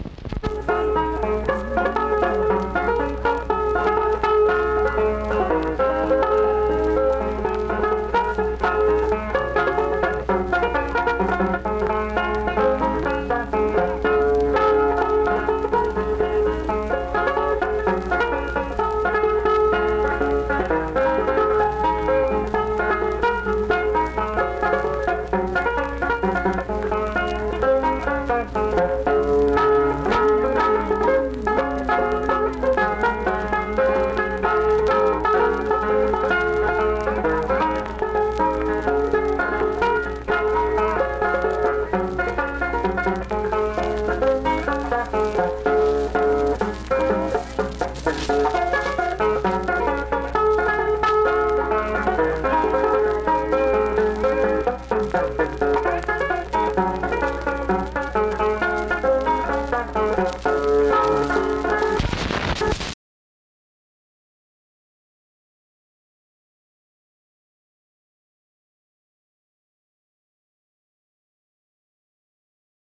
Instrumental banjo performance.
Instrumental Music
Banjo
Wood County (W. Va.), Vienna (W. Va.)